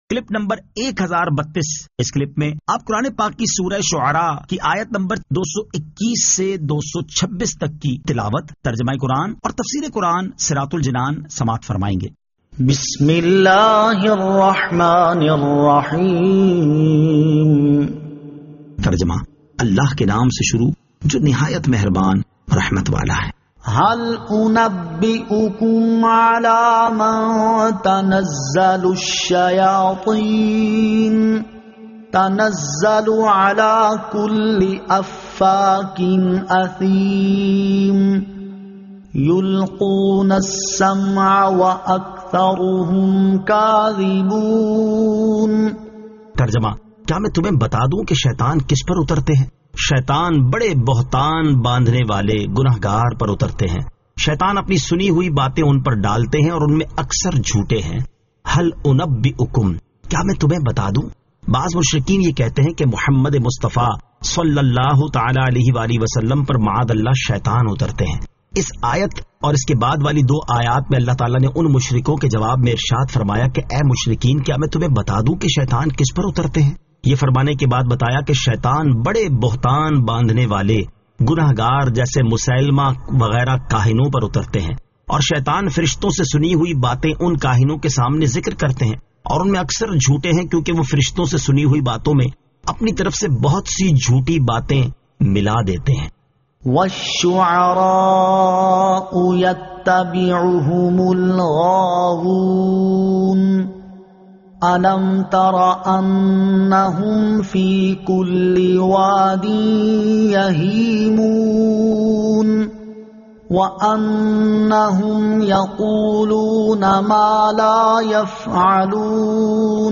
Surah Ash-Shu'ara 221 To 226 Tilawat , Tarjama , Tafseer